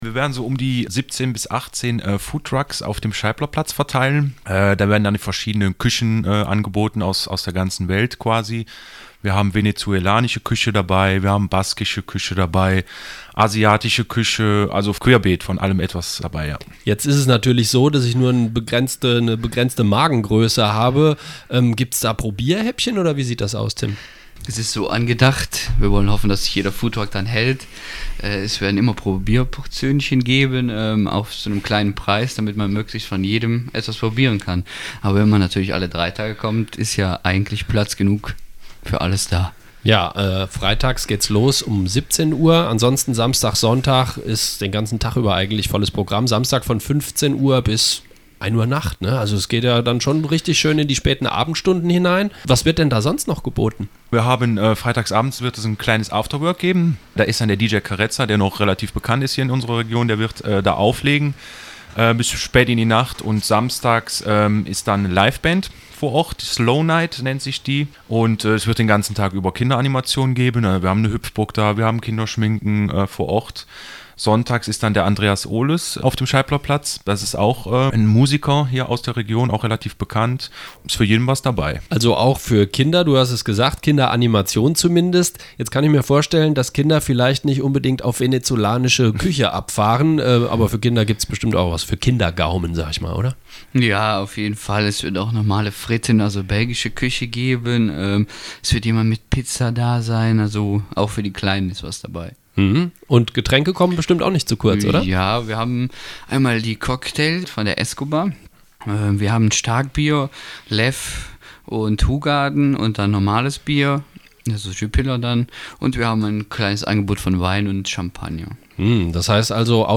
Beim Foodtruck Festival Ostbelgien erwarten Euch viele köstliche und frisch zubereitete Speisen aus der ganzen Welt! Im Gespräch